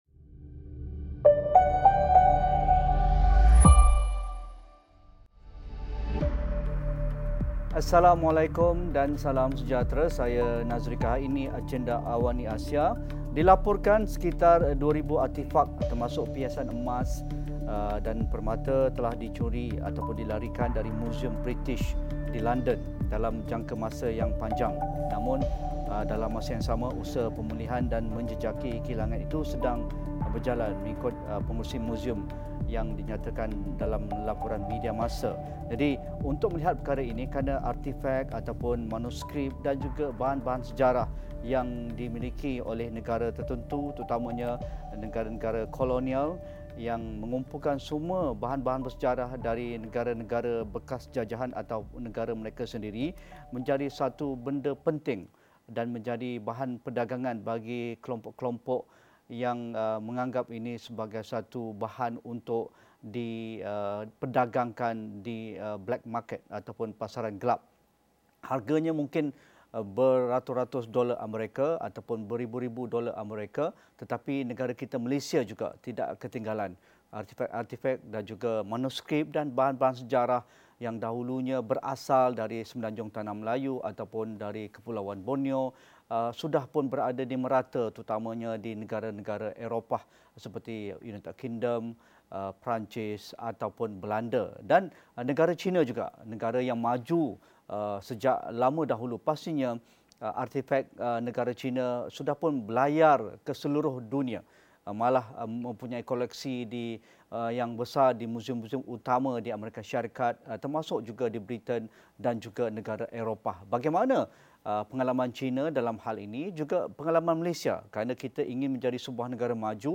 Sejauhmana usaha memulangkan artifak kepada negara asal dilakukan pihak berwajib? Ikuti diskusi dan analisis dalam Agenda AWANI 9.00 malam ini.